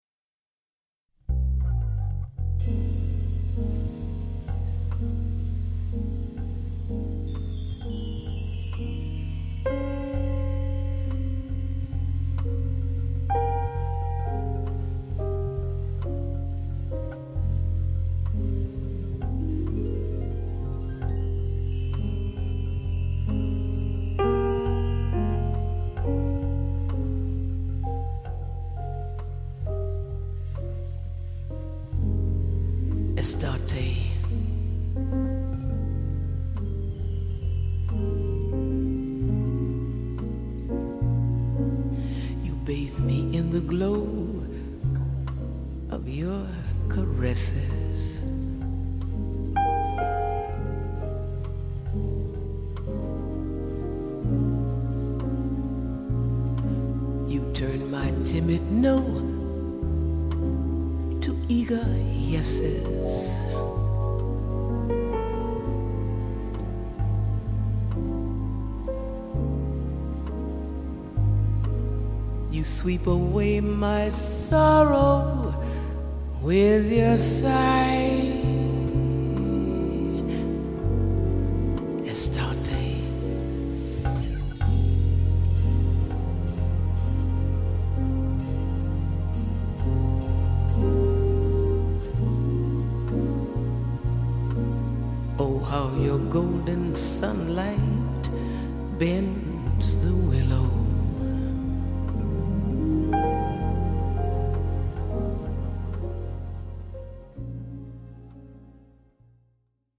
★ 八位爵士天后與六位爵士天王傳世名曲！